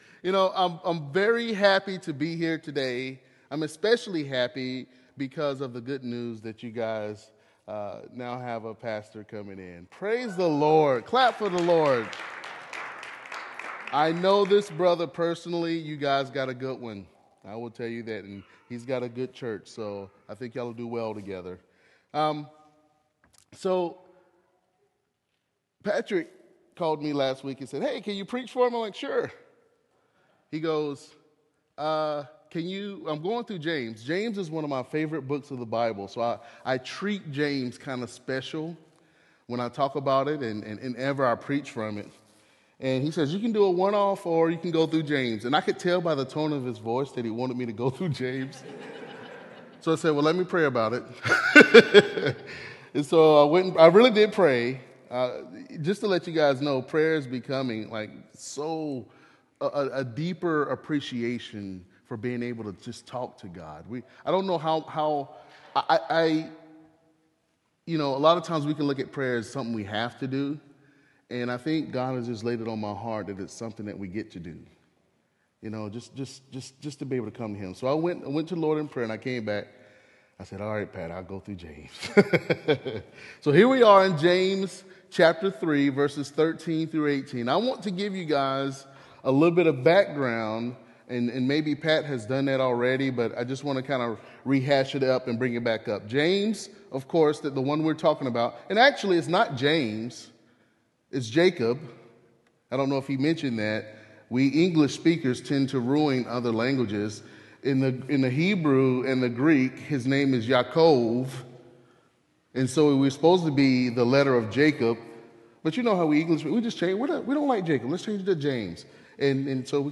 sermon-james-wisdom-that-brings-peace.m4a